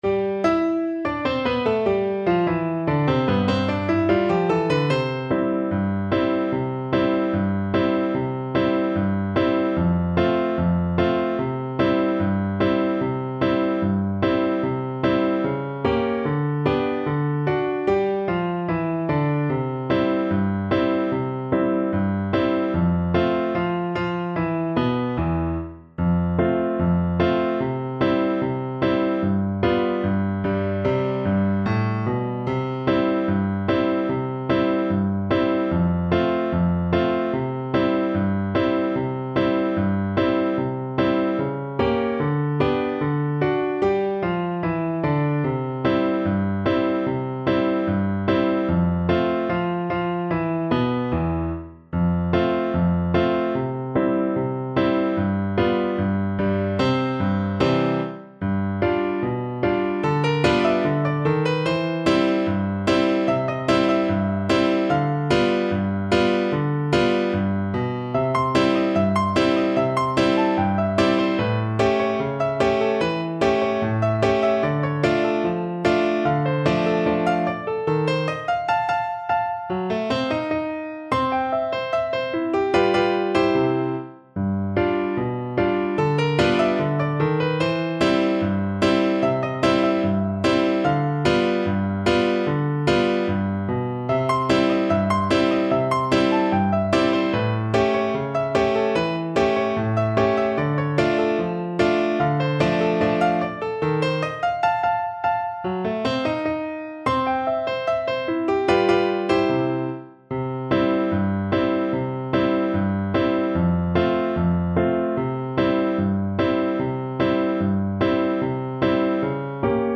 Play (or use space bar on your keyboard) Pause Music Playalong - Piano Accompaniment Playalong Band Accompaniment not yet available transpose reset tempo print settings full screen
C major (Sounding Pitch) (View more C major Music for Flute )
Not Fast = 74
2/4 (View more 2/4 Music)